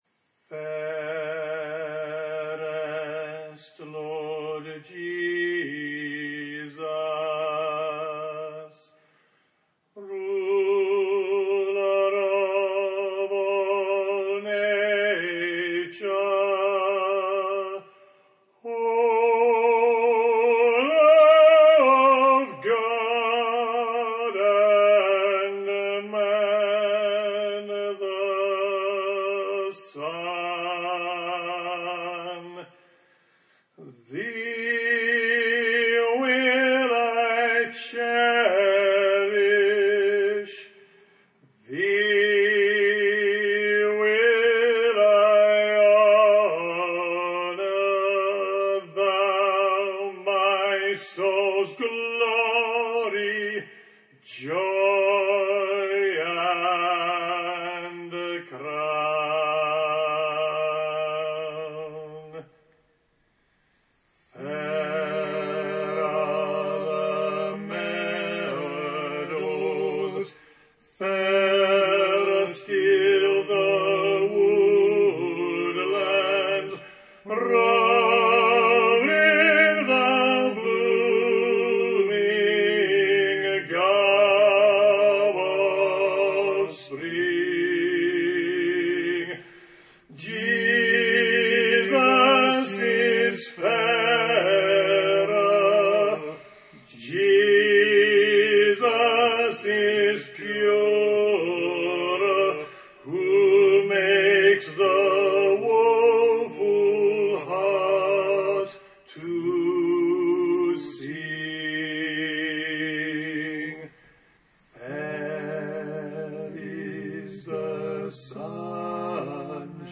On that day we chose one hymn each for the ceremony, and hers was, “Fairest Lord Jesus”.
It’s a bit rough around the edges, but I hope it pleases you to listen to it as much as it did me to put it together. http